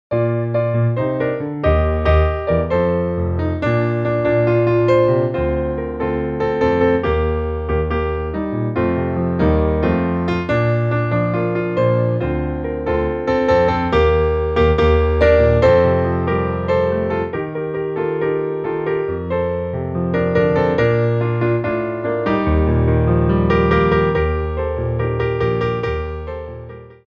Piano Arrangements of Pop & Rock for Ballet Class
4/4 (16x8)